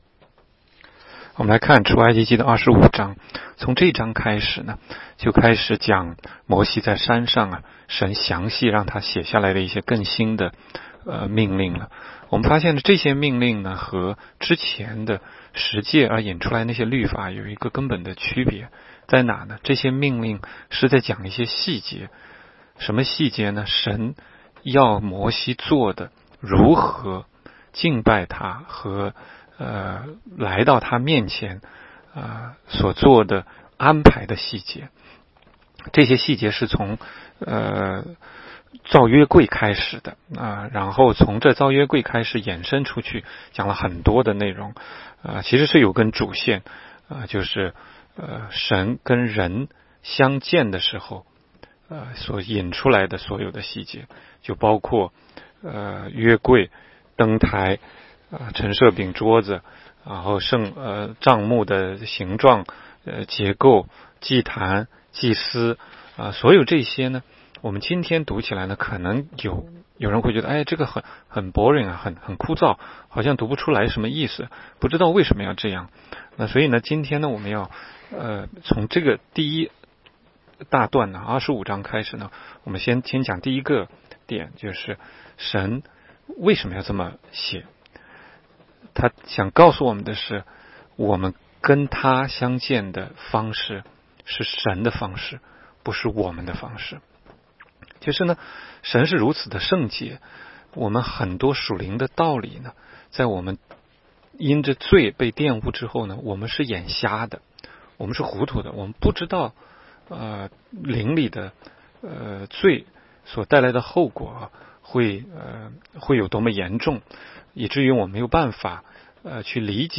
16街讲道录音 - 每日读经-《出埃及记》25章